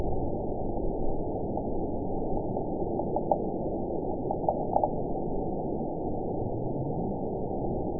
event 916960 date 02/22/23 time 04:19:55 GMT (2 years, 2 months ago) score 9.54 location TSS-AB03 detected by nrw target species NRW annotations +NRW Spectrogram: Frequency (kHz) vs. Time (s) audio not available .wav